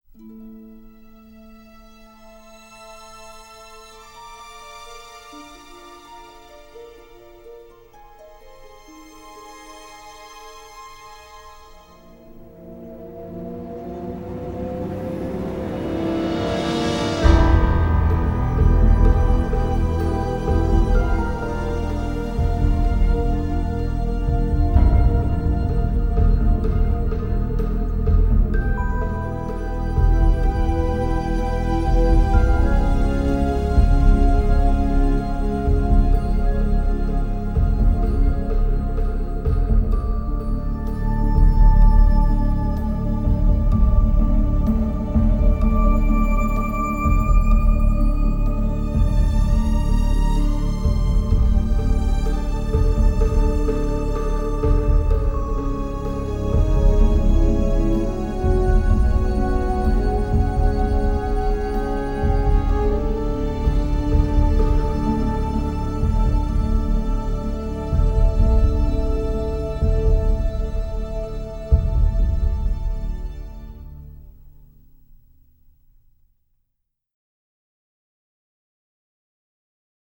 BSO